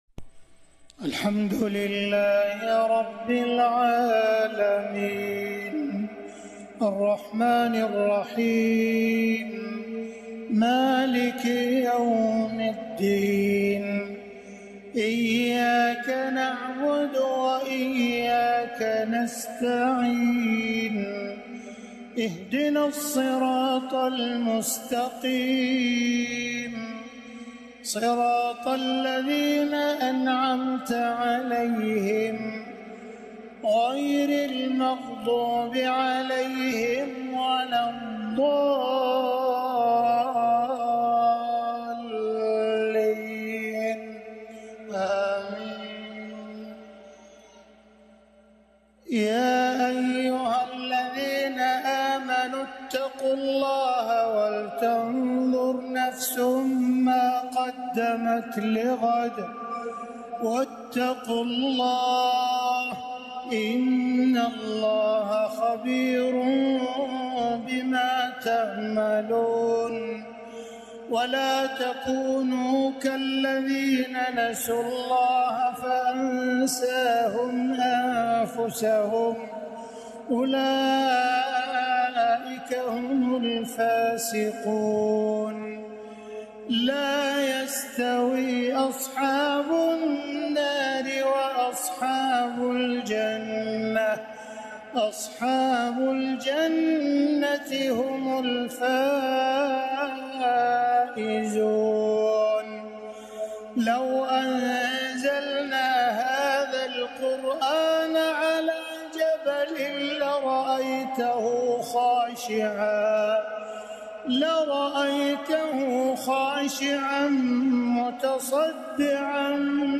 4. مشاركات الشيخ عبدالرحمن السديس خارج الحرم [ تلاوات وكلمات ]